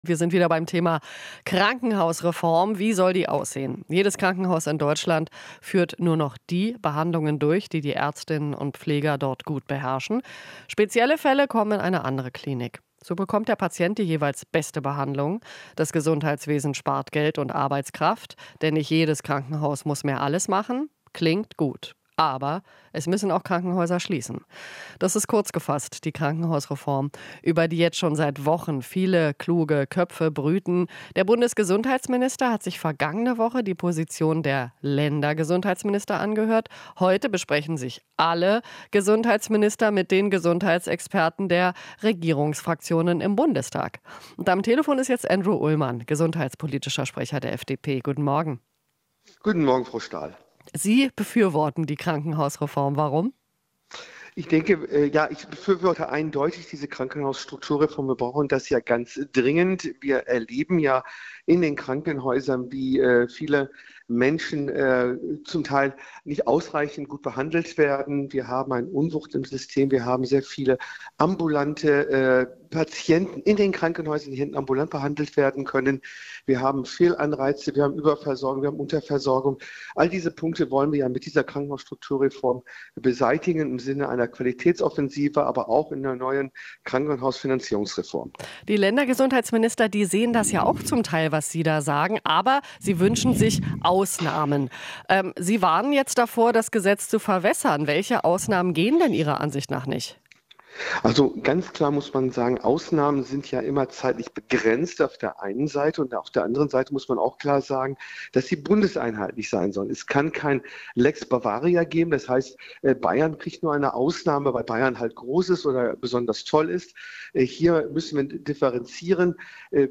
Interview - FDP-Gesundheitspolitiker: Keine Länder-Ausnahmen bei Klinikreform